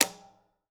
BUTTON_Click_Compressor_stereo.wav